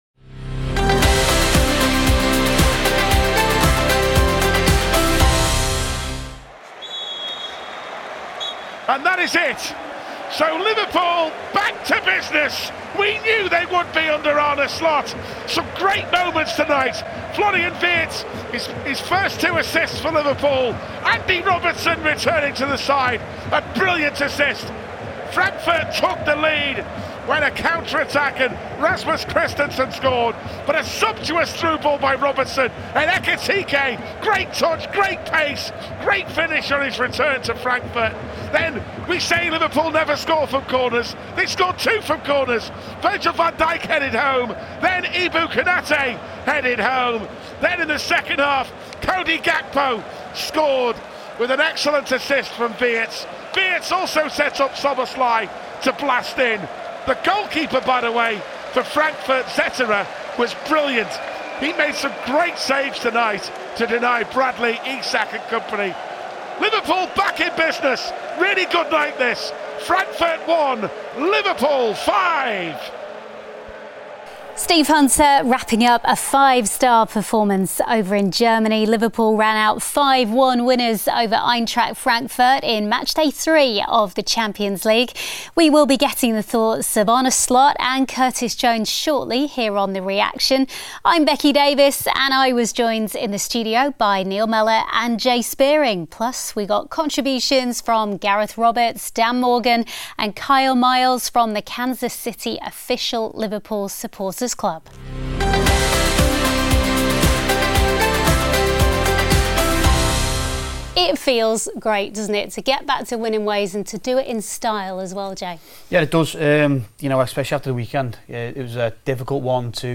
In this episode of The Reaction, we hear from Arne Slot and Curtis Jones, as well as post-match analysis from former Reds Jay Spearing and Neil Mellor.